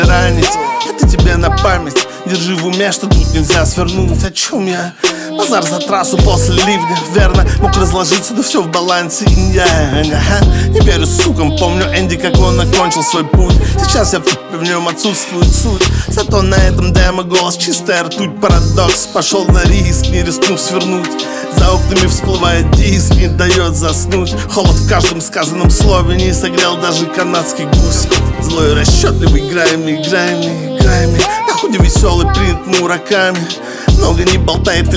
Жанр: Хип-Хоп / Рэп / Русский рэп / Русские
Hip-Hop, Rap